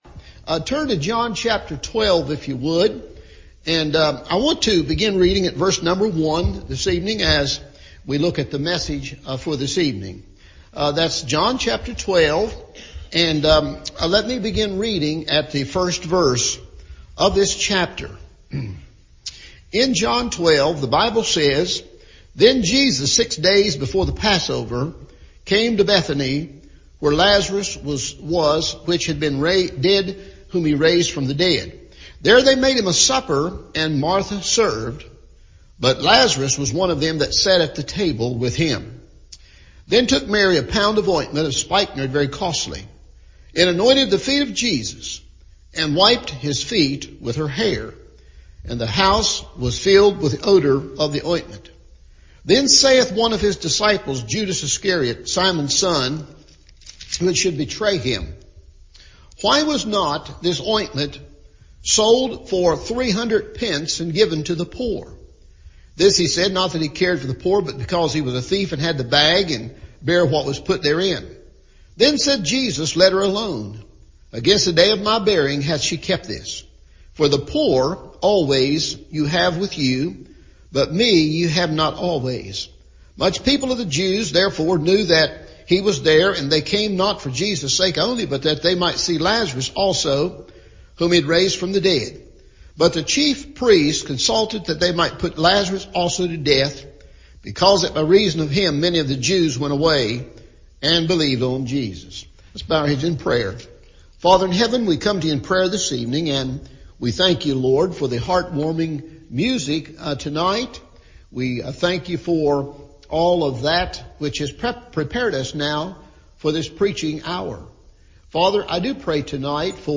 Broken and Spilled Out – Evening Service